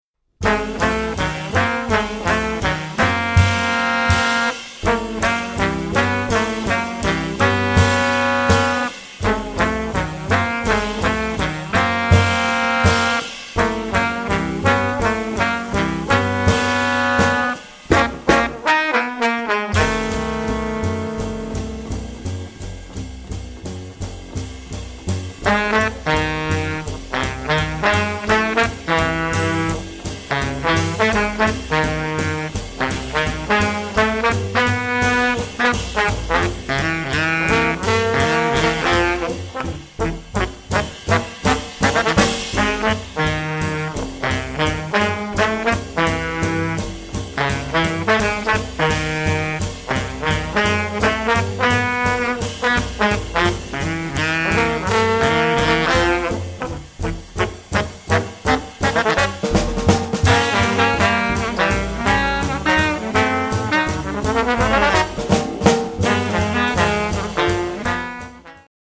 The Best In British Jazz
Recorded Doz Studios, Tunbridge Wells November 2008